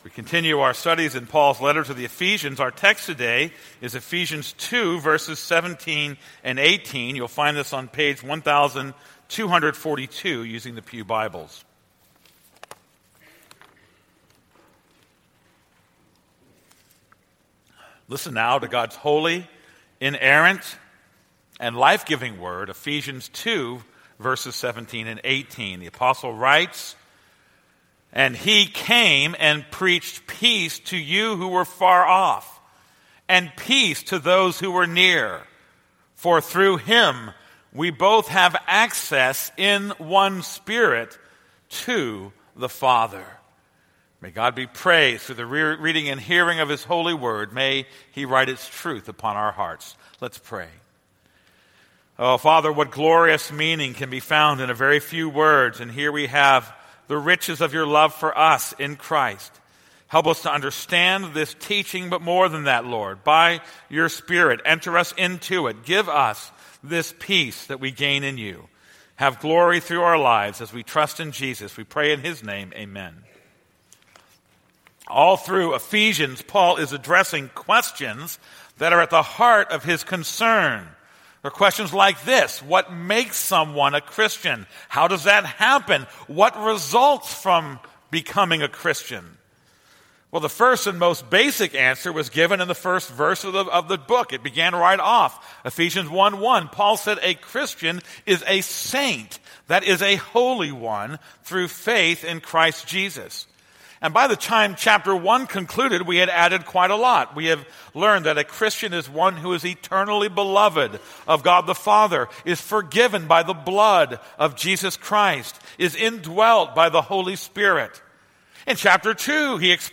This is a sermon on Ephesians 2:17-18.